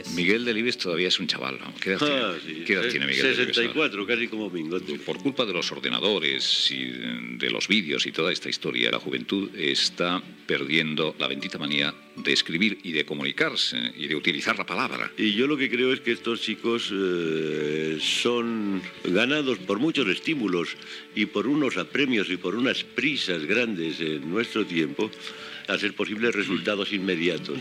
Fragment d'una entrevista a l'escriptor Miguel Delibes.
Info-entreteniment